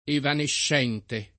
[ evanešš $ nte ]